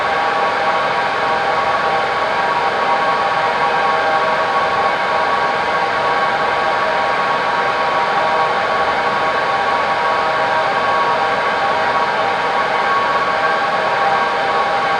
EC120_Fenestron-left.wav